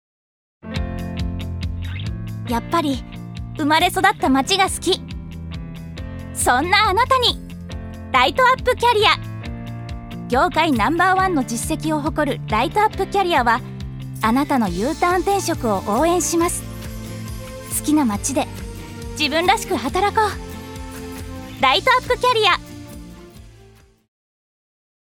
預かり：女性
ナレーション１